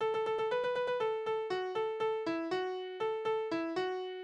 Kinderspiele: Der Wolf ist da Alle meine Hule - ganskens Kummt na Hus!
Tonart: D-Dur Taktart: 2/4 Tonumfang: Quinte Sprache